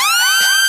Kill Bill Ironside Siren.wav